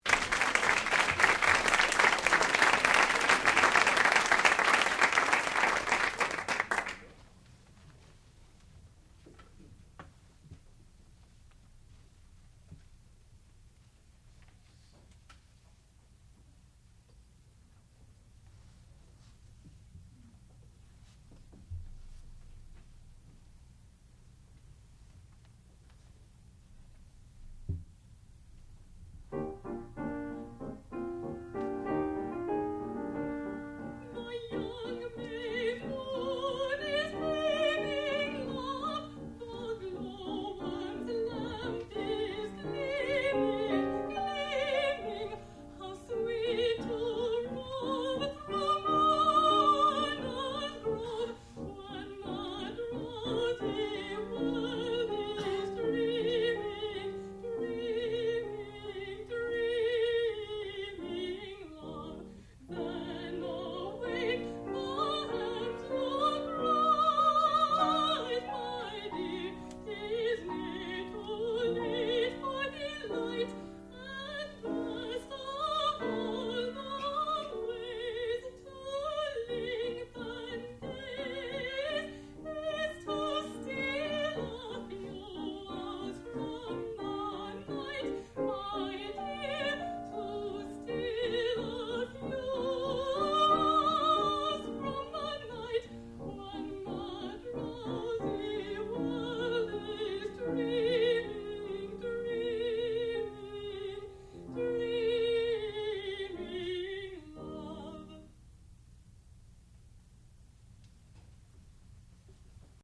sound recording-musical
classical music
piano
mezzo-soprano
Master's Recital